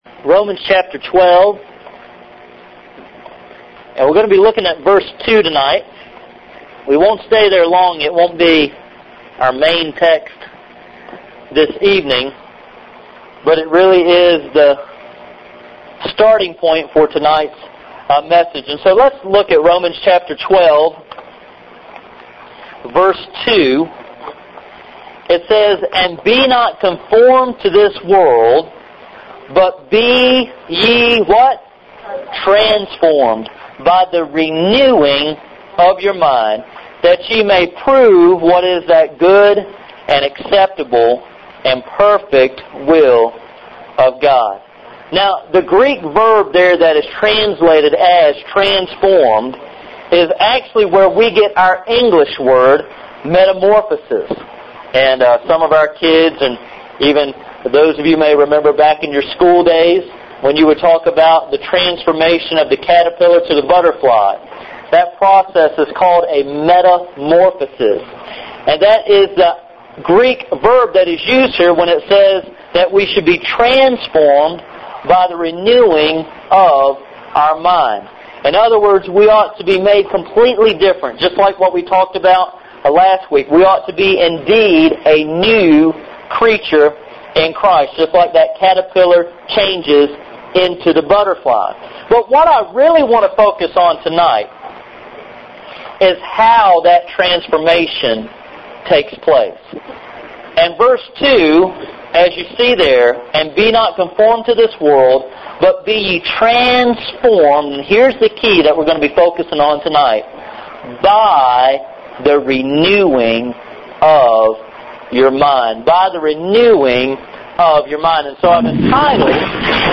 Here you will find audio from the various services.